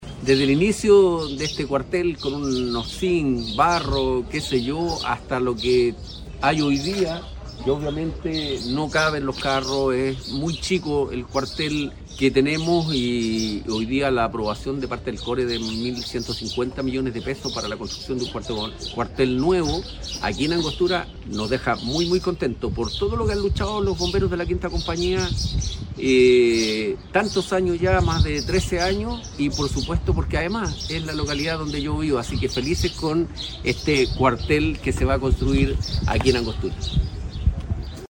Este proyecto, largamente esperado por la comunidad y los voluntarios de la Quinta Compañía, fue valorado por el alcalde Pablo Silva Pérez quien se mostró muy satisfecho con la aprobación de los recursos y destacó la importancia de este proyecto para la comuna.